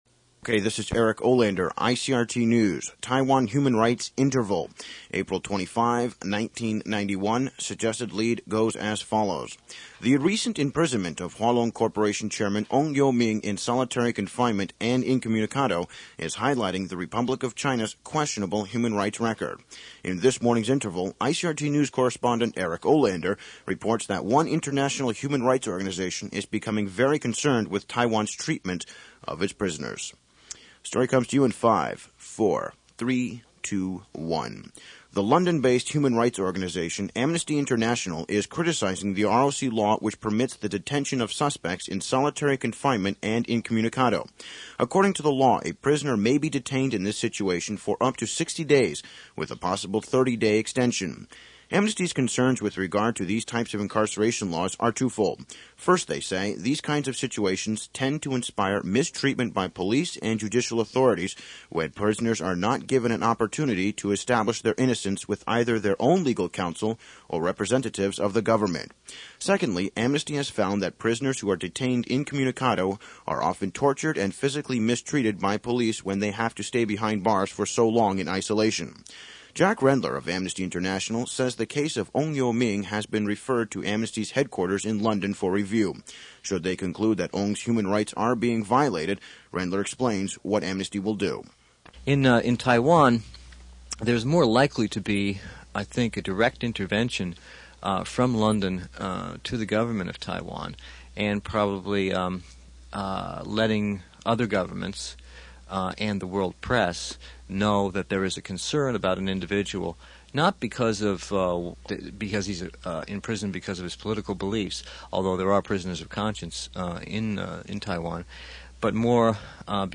The clips below are from time reporting out of my dorm room at USC in LA or from my parents bedroom in Berkeley.  Yes, it was a bit crude but it was the best journalism education anywhere on the planet.